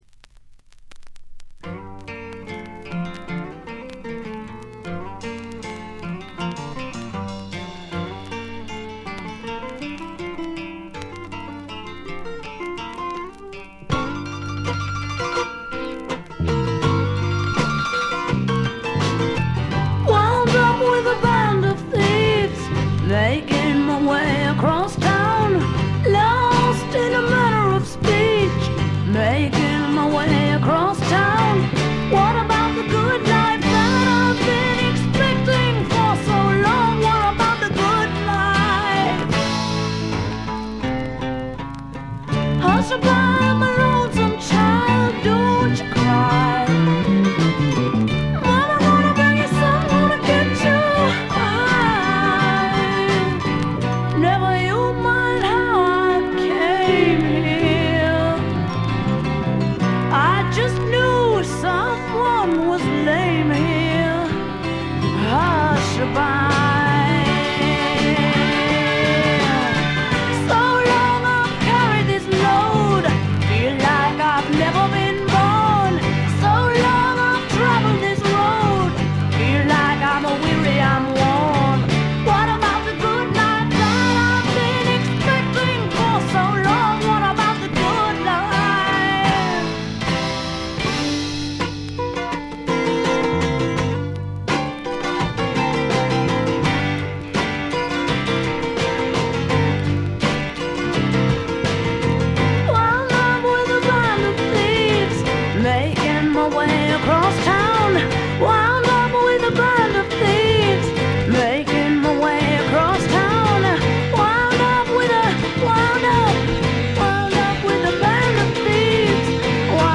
見た目に反してバックグラウンドノイズ、チリプチ多め大きめ。
カナダ出身の女性シンガーソングライターが残したサイケ／アシッド・フォークの大傑作です。
試聴曲は現品からの取り込み音源です。